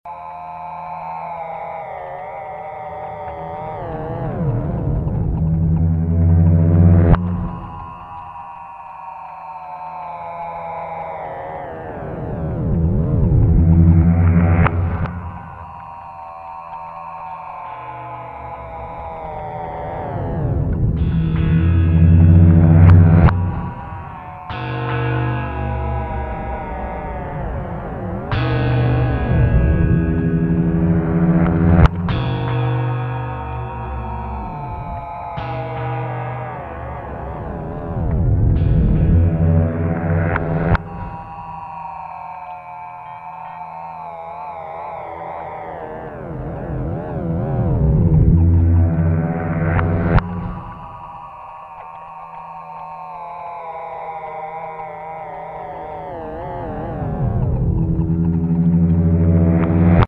Everything you hear was recorded from an electric guitar. Heavy ring modulation and other processing was done, and this is the result. I would grab the two low strings, (E and A) and pull them out as far as I could, then snap release them. the resulting dissonant clanging twang sound was then reversed, which is why the sounds seem to build up to a fevered pitch and then stop suddenly.